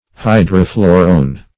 Search Result for " hydrophlorone" : The Collaborative International Dictionary of English v.0.48: Hydrophlorone \Hy`dro*phlo"rone\, n. [Hydro-, 2 + phlorone.]
hydrophlorone.mp3